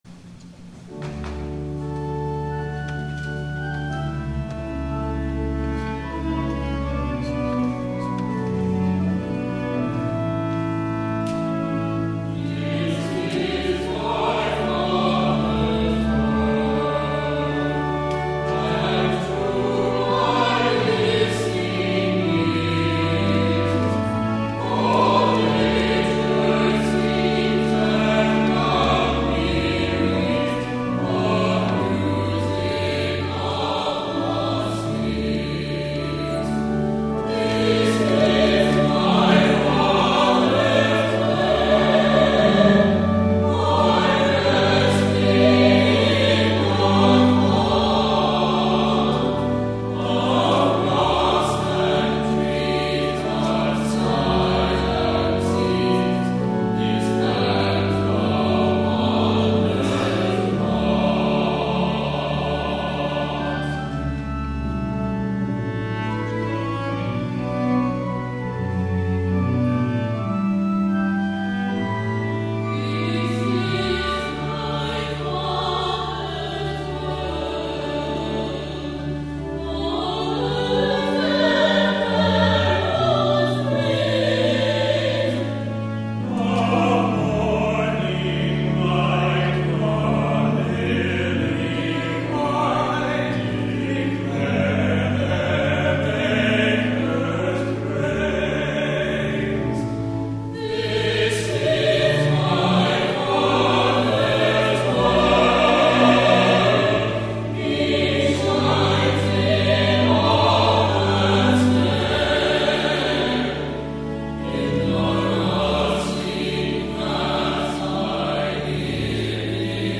2nd SUNDAY OF EASTER
THE ANTHEM